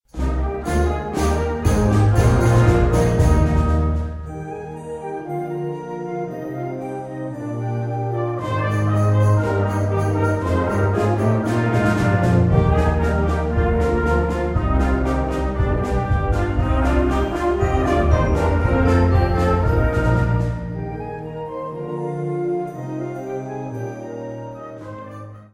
Categoria Concert/wind/brass band
Sottocategoria Musica per concerti
Allegro 2:24